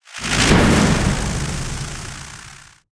🌲 / foundry13data Data modules soundfxlibrary Combat Single Spell Impact Lightning
spell-impact-lightning-1.mp3